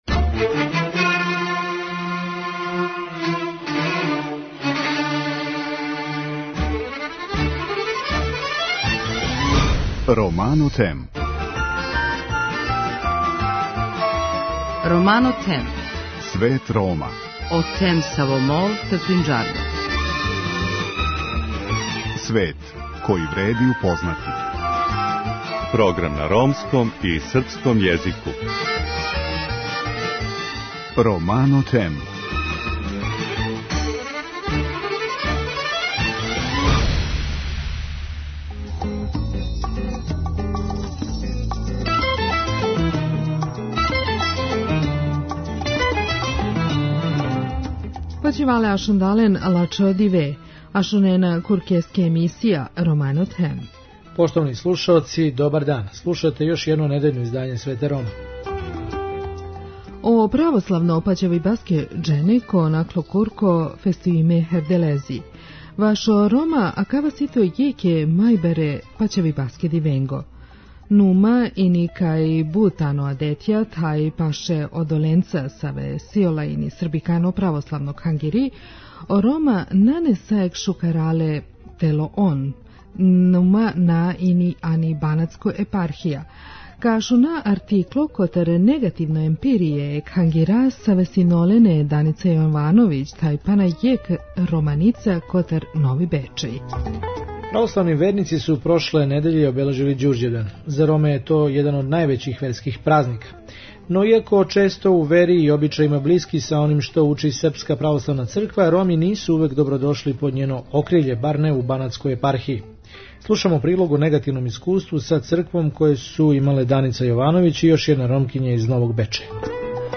преузми : 7.19 MB Romano Them Autor: Ромска редакција Емисија свакодневно доноси најважније вести из земље и света на ромском и српском језику. Бави се темама из живота Рома, приказујући напоре и мере које се предузимају за еманципацију и интеграцију ове, највеће европске мањинске заједнице.